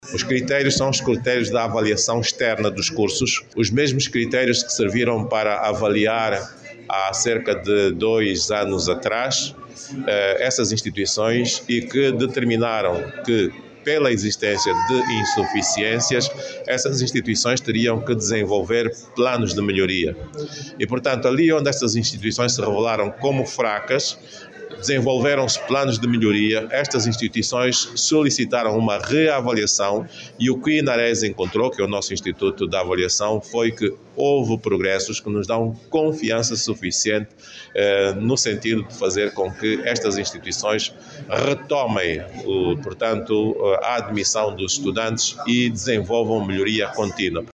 O Ministro do Ensino Superior, Ciência, Tecnologia e Inovação, Albano Ferreira, defende, a necessidade de fomentar o espírito empreendedor nas Instituições de Ensino Superior. A ideia segundo o Ministro Albano Ferreira é transformar ideias inovadoras em projectos sustentáveis com impacto económico e social real. Albano Ferreira falava ontem na abertura do Workshop Nacional sobre Empreendedorismo, que decorre sob o lema “Fomentar o espírito empreendedor e dar vida a projectos inovadores nas instituições de ensino superior.